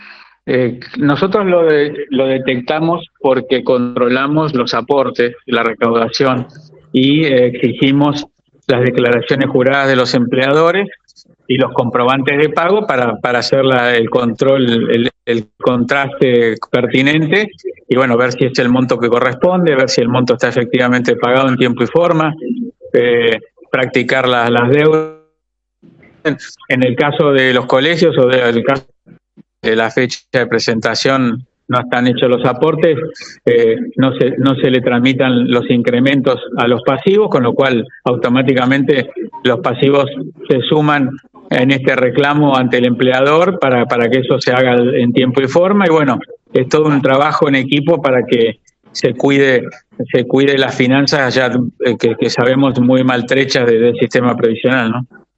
Gastón Bagnat, presidente de la Caja de Jubilaciones y Pensiones de Entre Ríos, se refirió al tema en una entrevista con Radio RD 99.1 FM.